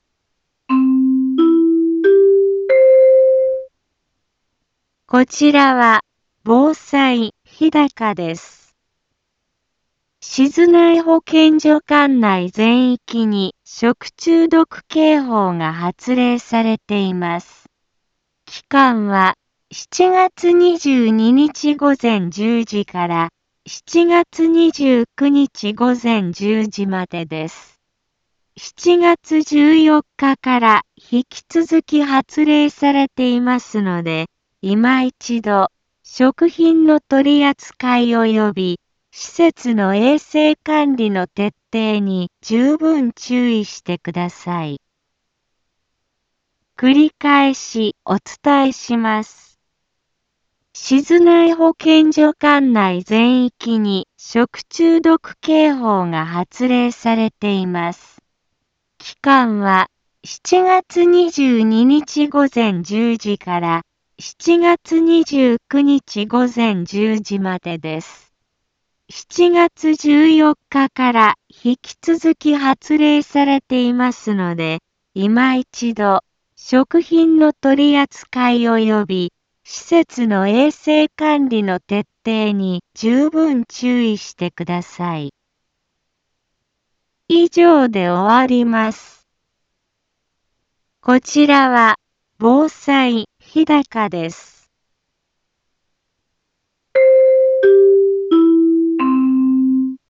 一般放送情報
Back Home 一般放送情報 音声放送 再生 一般放送情報 登録日時：2025-07-22 15:03:32 タイトル：食中毒警報の発令について インフォメーション： こちらは、防災日高です。 静内保健所管内全域に食中毒警報が発令されています。